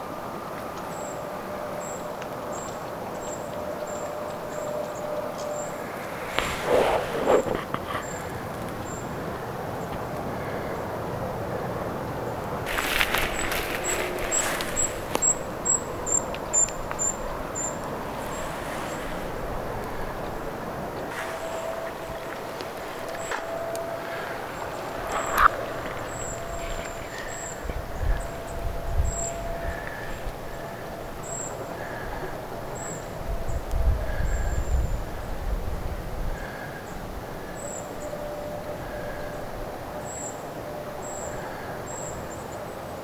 kaksi eri tyyppistä puukiipijälintua lähekkäin.
niillä on erilaiset siritystyyppiset äänet?
ehka_kahden_lahekkaisen_puukiipijalinnun_kaksi_erilaista_siristysaantelya_toinen_on_ti-siritystyyppia.mp3